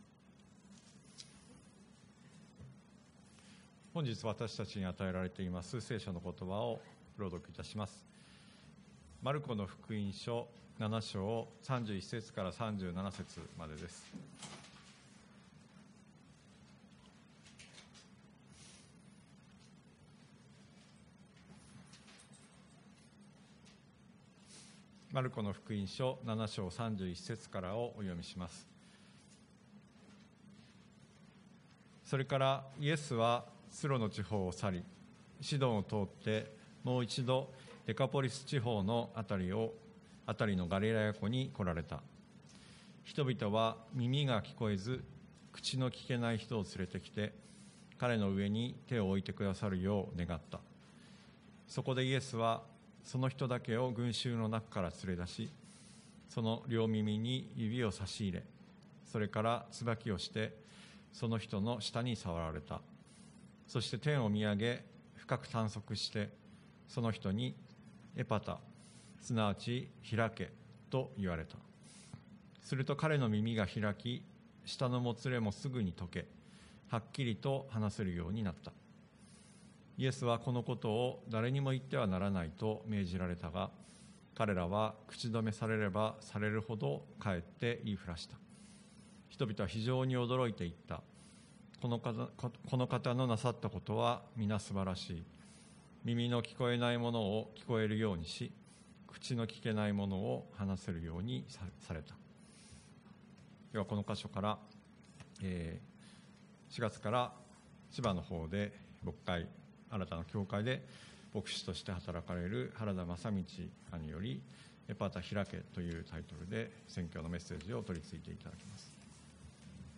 TOP > 礼拝メッセージ(説教) > エパタ・ひらけ エパタ・ひらけ 2022 年 2 月 6 日 礼拝メッセージ(説教
主日礼拝